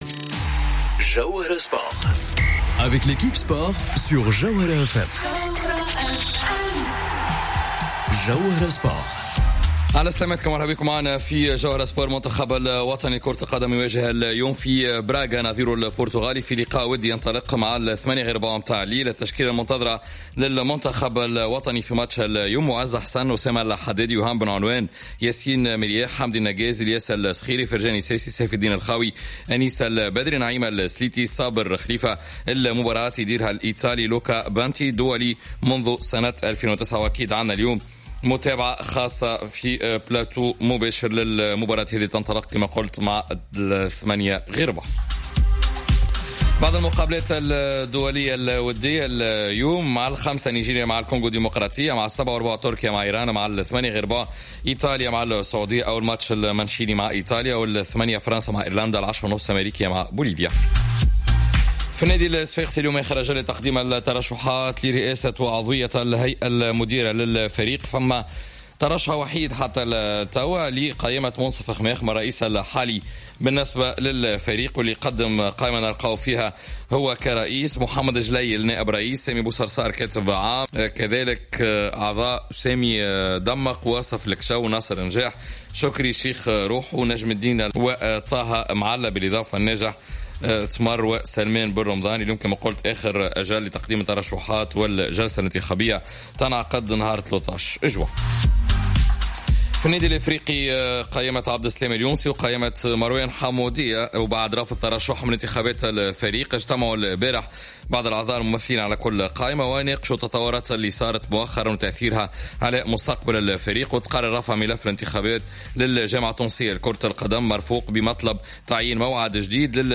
نشرة أخبار منتصف النهار اليوم الإثنين 28 ماي 2018